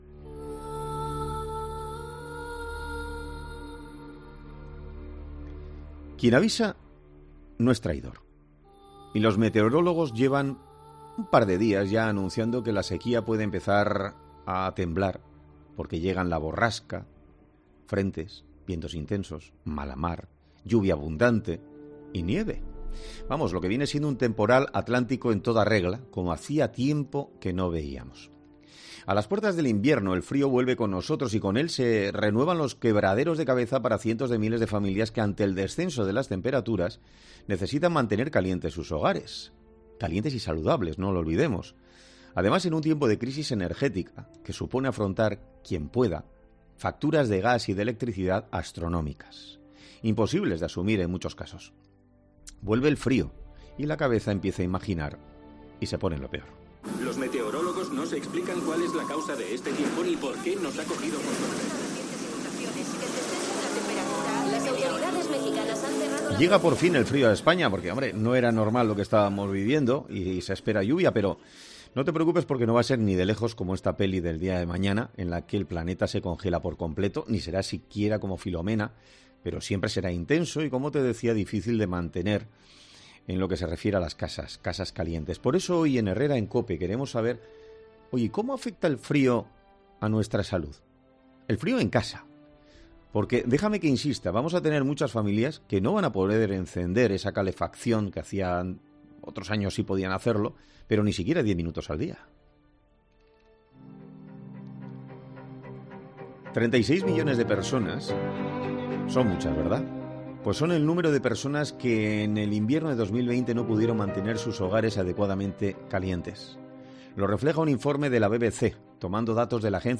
Un experto en salud habla sobre cómo nos afecta el frío: "Aumenta la mortalidad en las poblaciones"